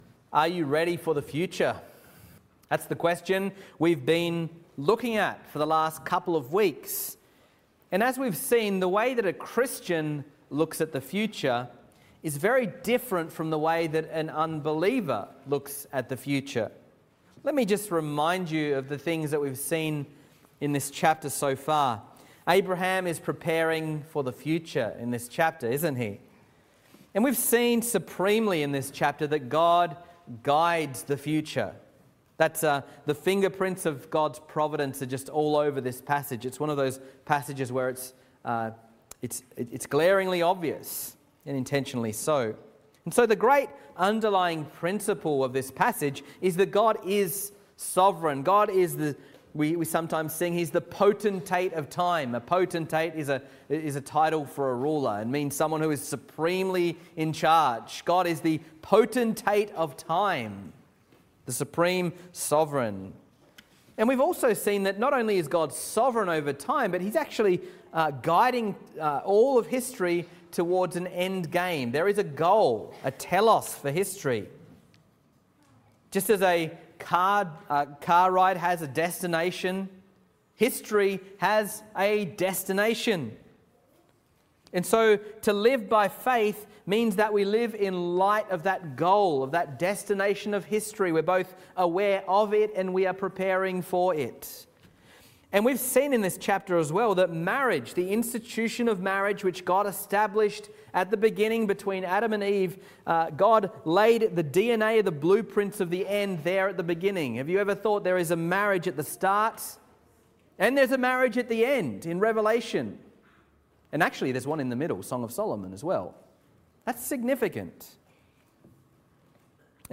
Sermons | Reformed Church Of Box Hill
Morning Service